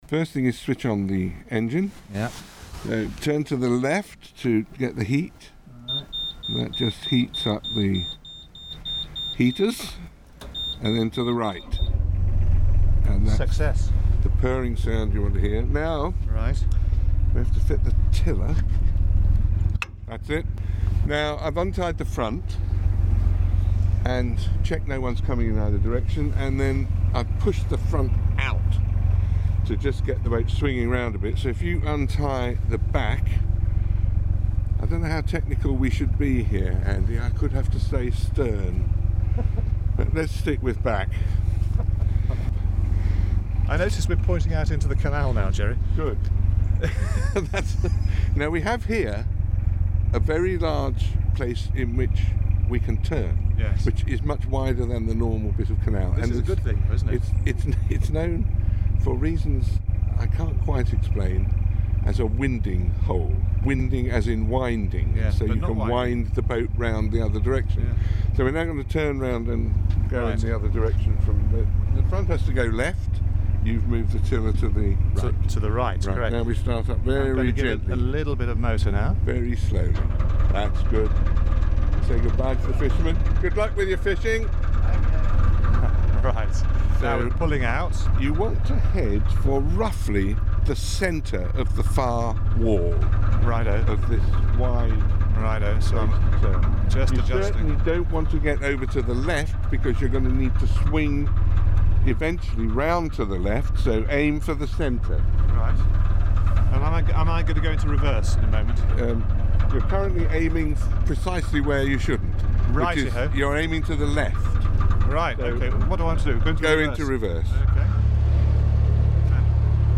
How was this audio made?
Anyway, as befits two old Radio 4 hands here’s some audio of my early lessons in boatcraft.